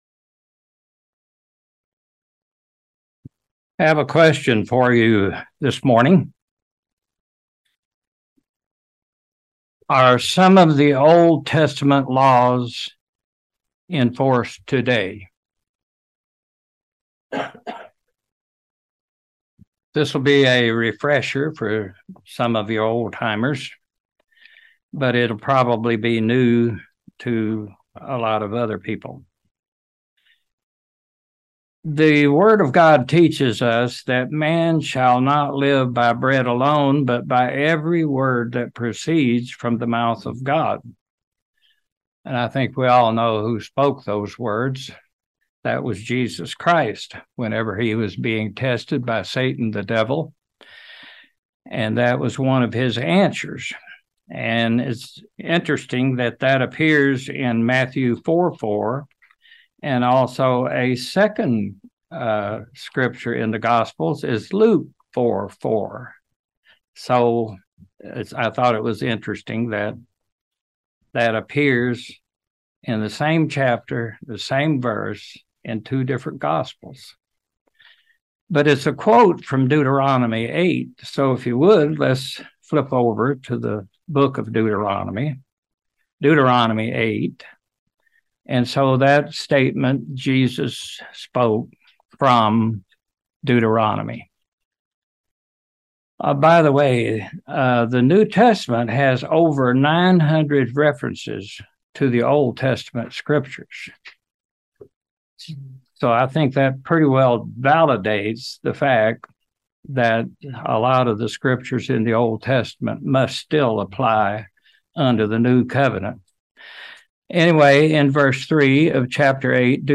Given in London, KY